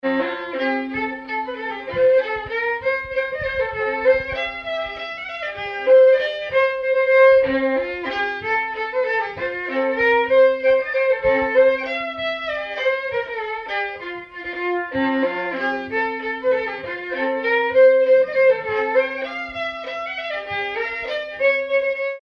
violon
danse : valse
bal, dancerie